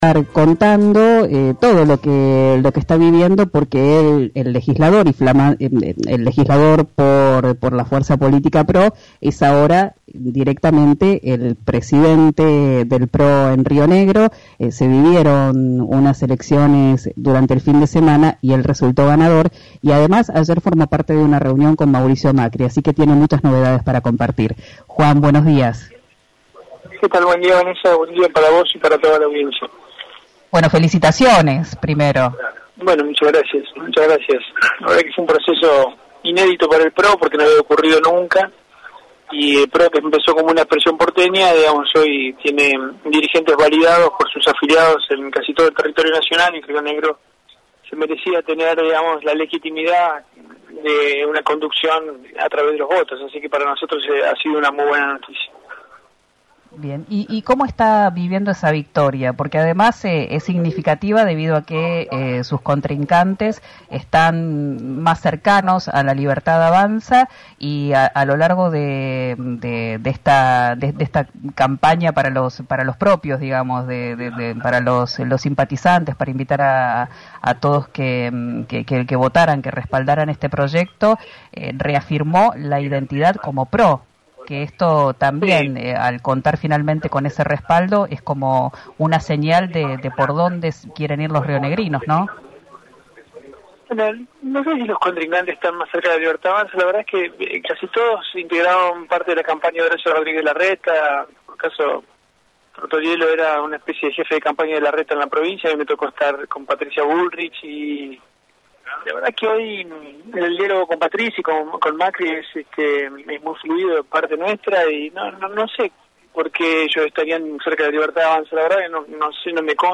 Escuchá a Juan Martín, en RÍO NEGRO RADIO
El legislador y flamante presidente del PRO en Río Negro, Juan Martin, adelantó este jueves en RÍO NEGRO RADIO que el partido amarillo tiene casi cerrada la idea de conformar una alianza con La Libertad Avanza (LLA) a nivel nacional.